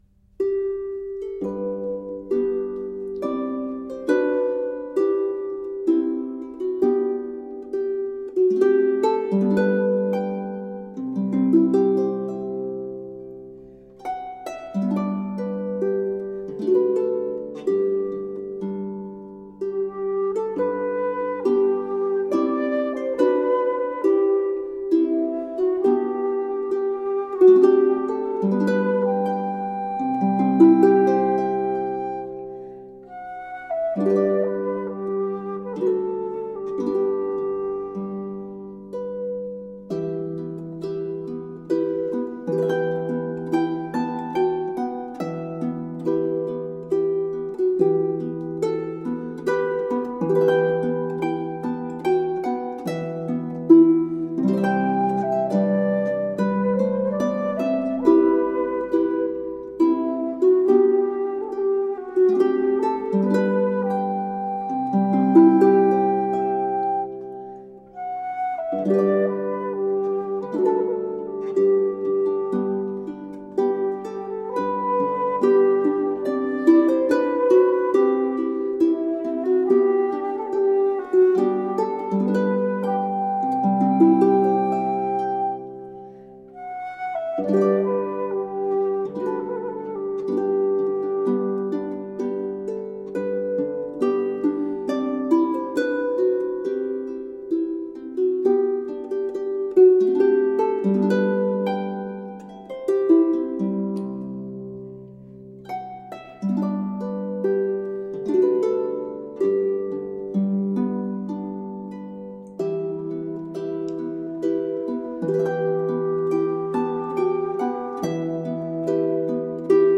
Early music for healing.